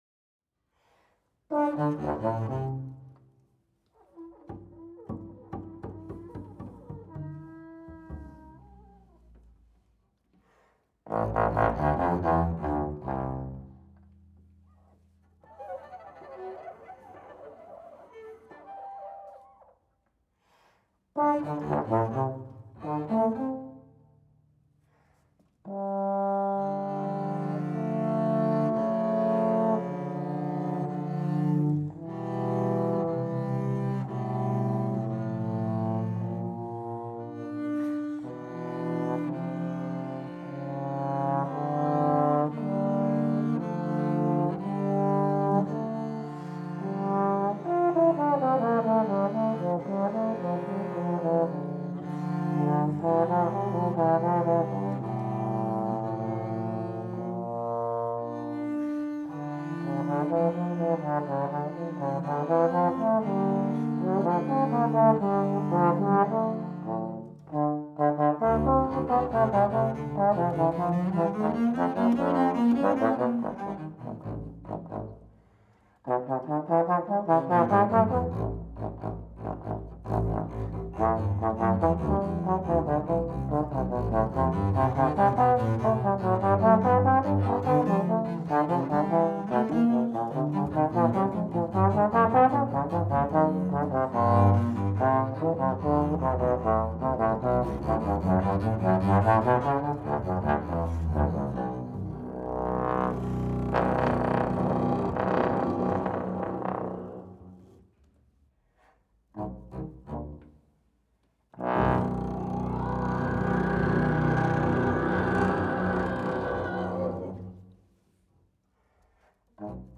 bass trombone